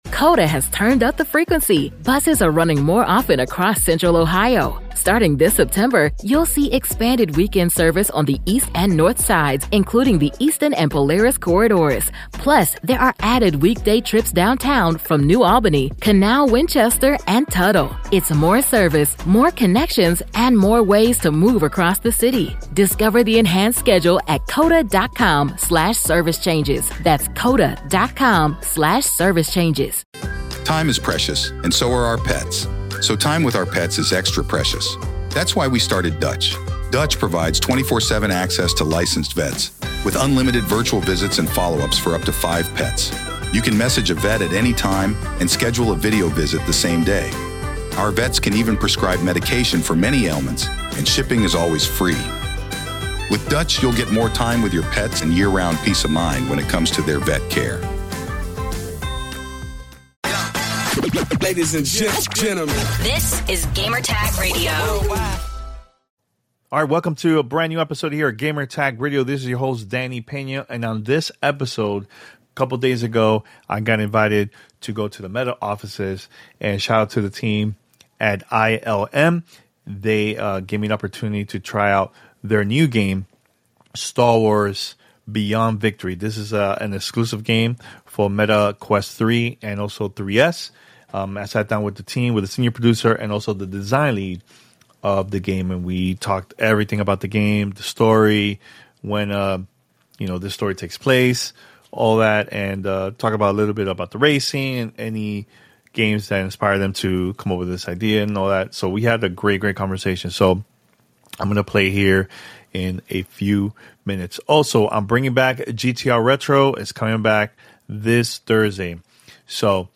Star Wars: Beyond Victory - A Mixed Reality Playset Dev Interview